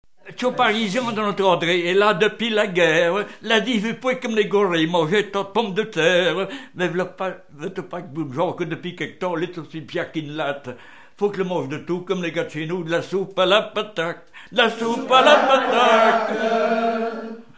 Lettrées patoisantes
Pièce musicale inédite